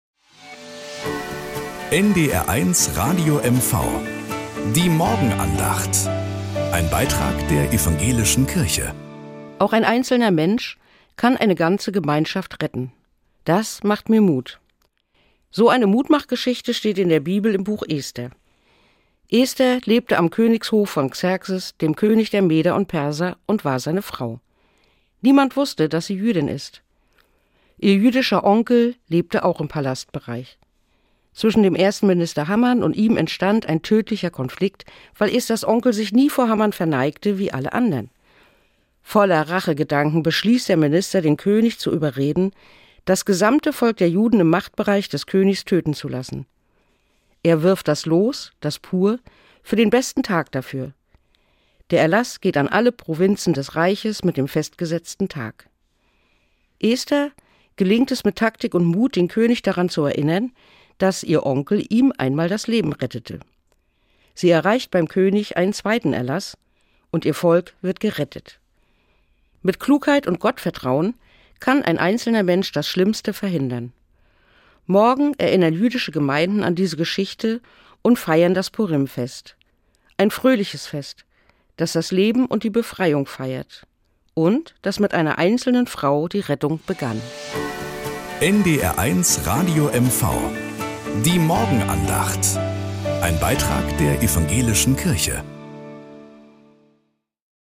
Nachrichten aus Mecklenburg-Vorpommern - 30.04.2025